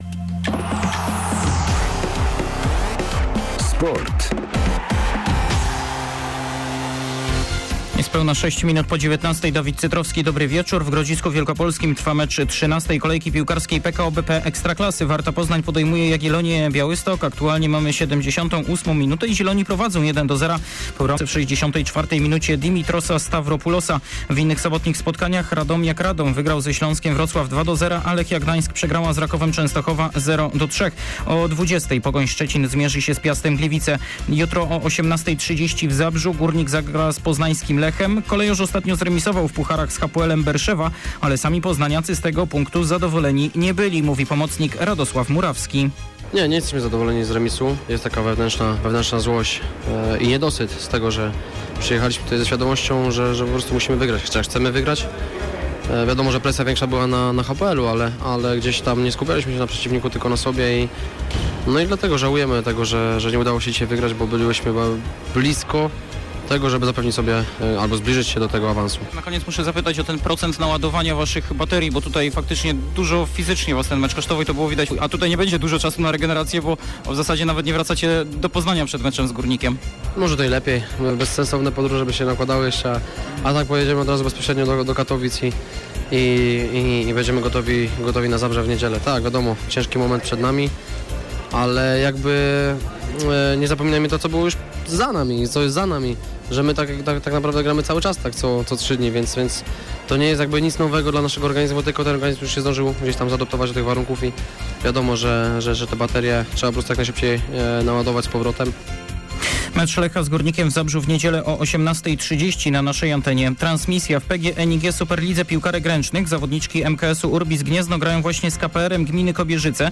serwis sportowy z 15 października